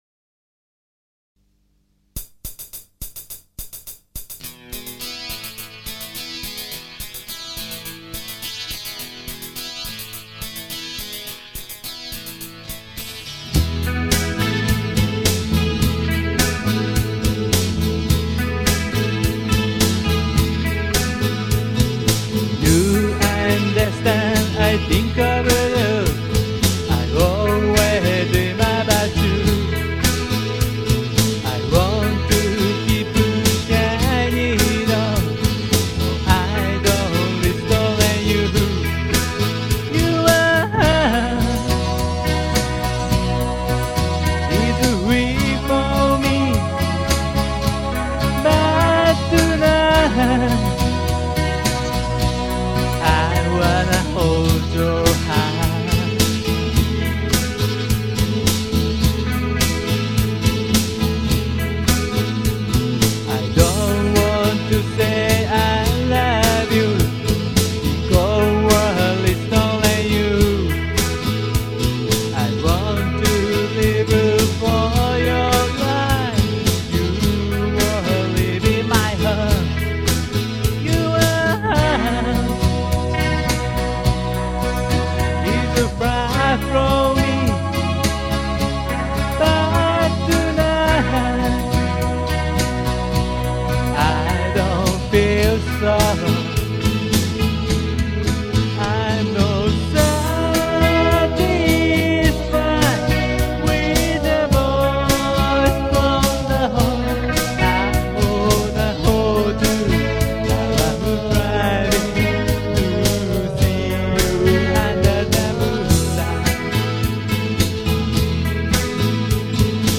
ロマンチックなバラード。